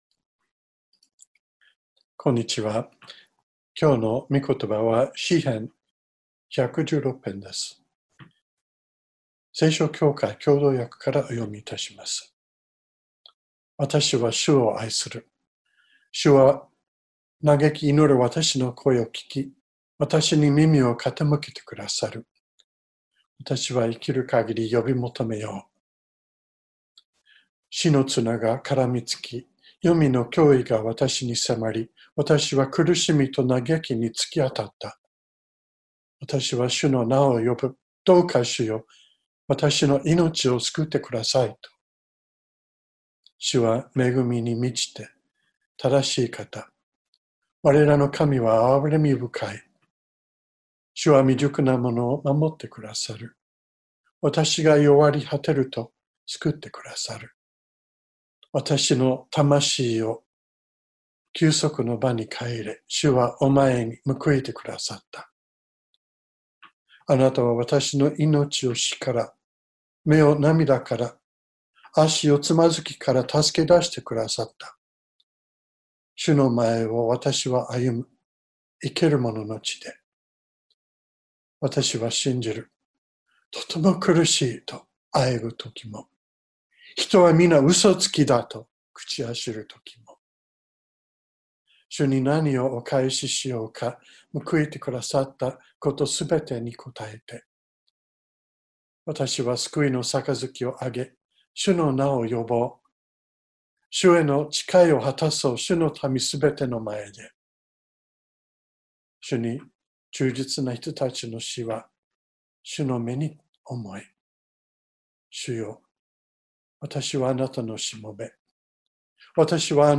2020年08月23日朝の礼拝「祈りに応える方」川越教会
音声ファイル 礼拝説教を録音した音声ファイルを公開しています。